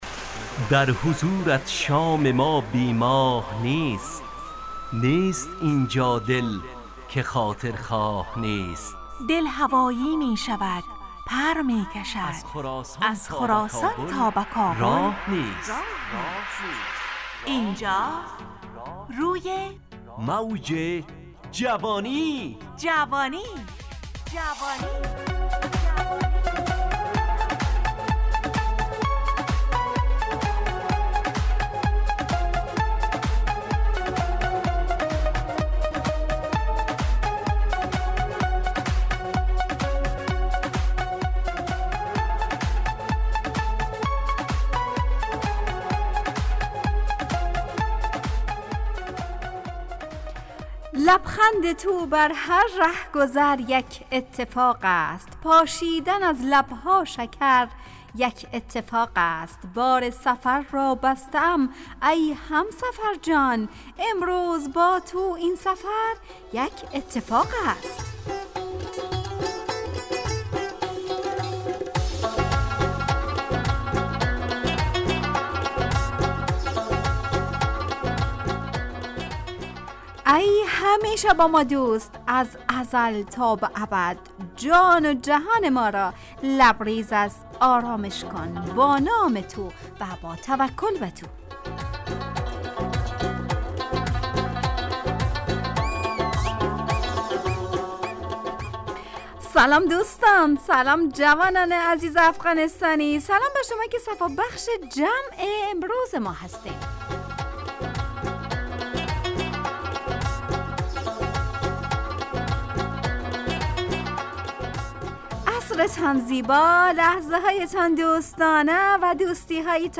همراه با ترانه و موسیقی مدت برنامه 55 دقیقه . بحث محوری این هفته (دوستی) تهیه کننده